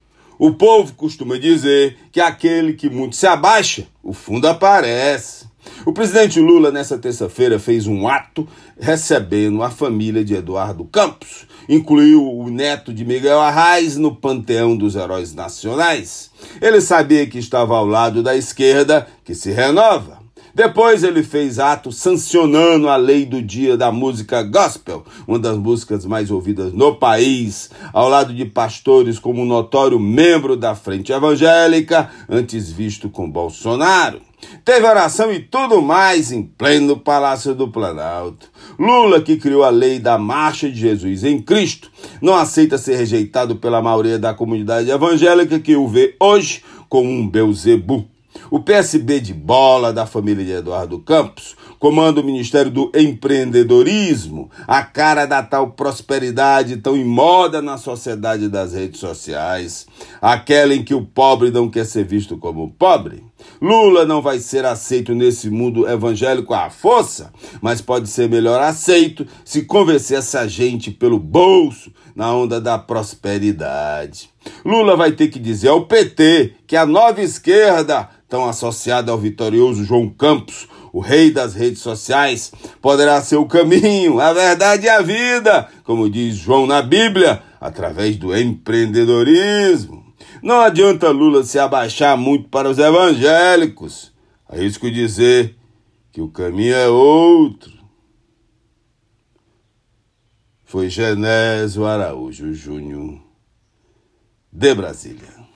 Comentário desta quarta-feira (16/10/24)